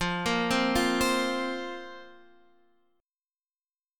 Listen to Fsus4 strummed